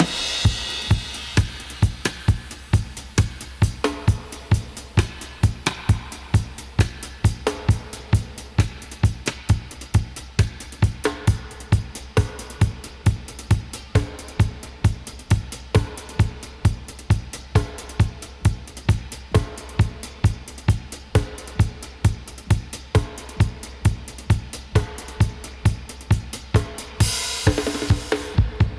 131-DUB-04.wav